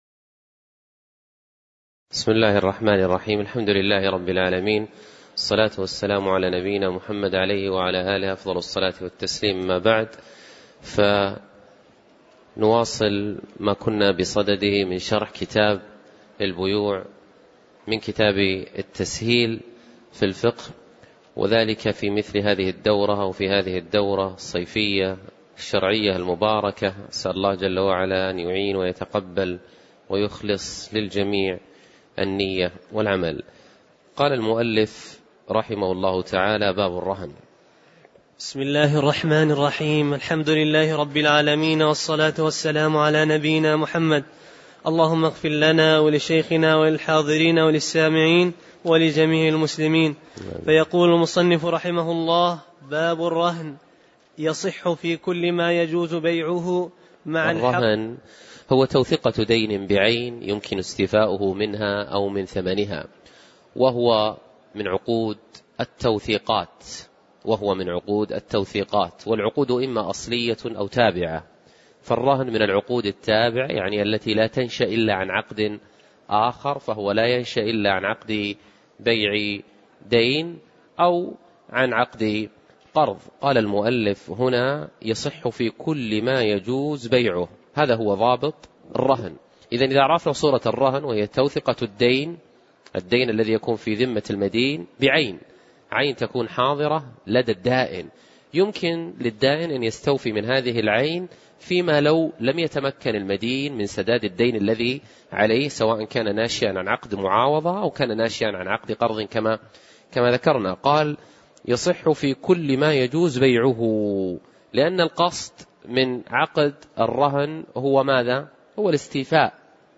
تاريخ النشر ١٩ شوال ١٤٣٩ هـ المكان: المسجد النبوي الشيخ